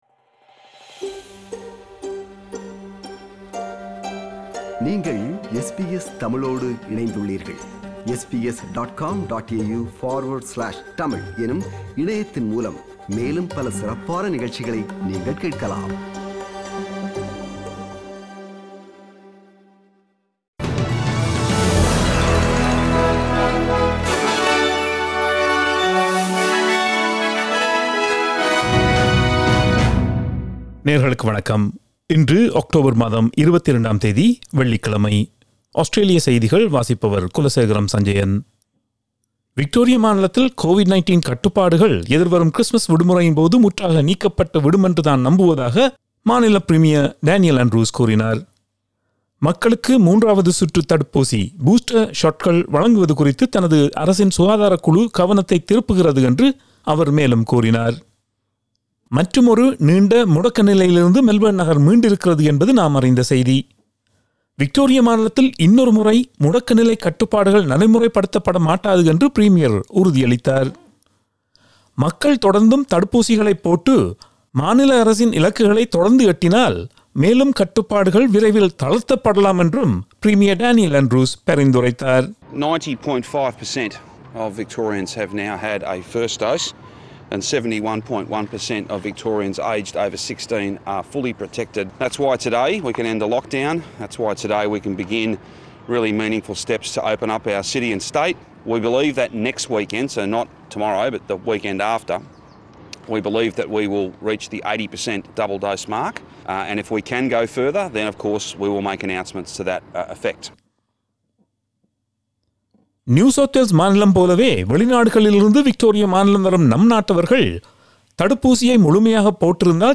Australian news bulletin for Friday 22 October 2021.